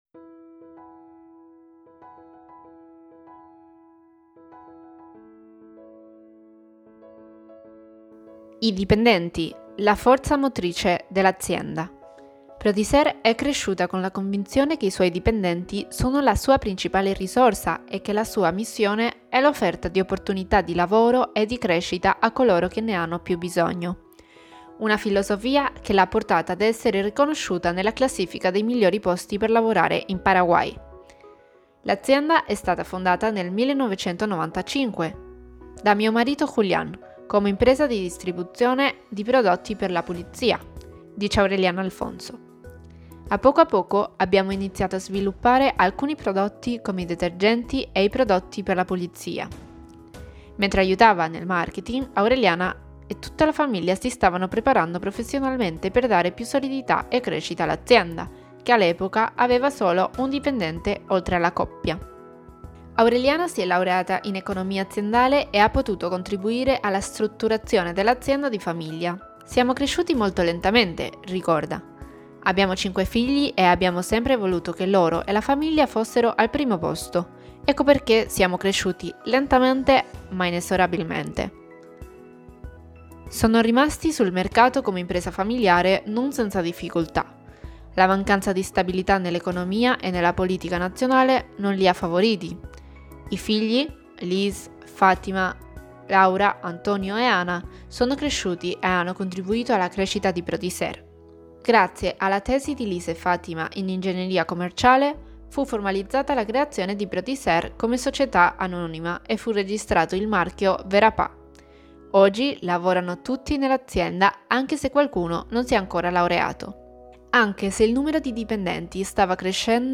Persona e famiglia > Audioletture